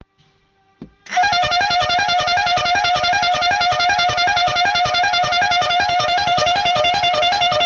Fisa luchthoorn Traffic Plastic FT2-3P 24V117dB250W815Hz high tone730Hz middle tone600Hz low tone